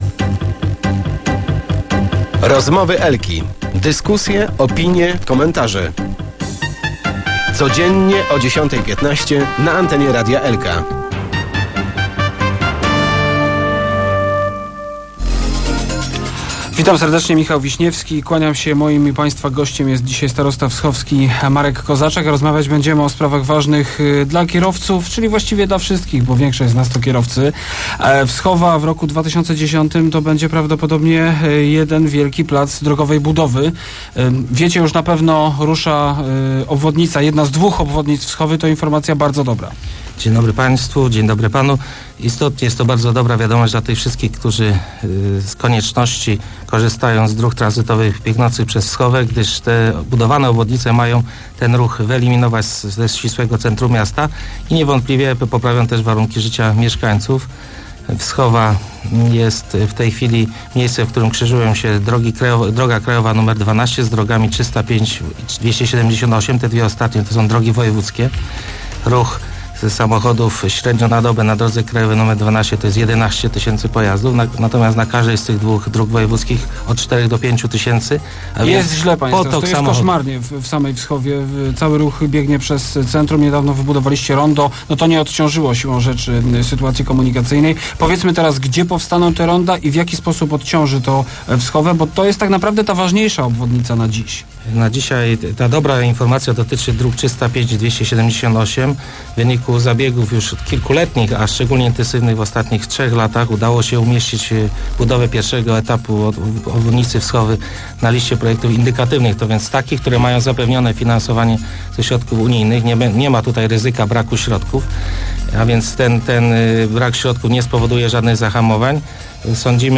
– Te inwestycje zakończą trwające od lat  rozjeżdżanie miasta – twierdzi dzisiejszy gość Rozmów Elki, starosta wschowski, Marek Kozaczek.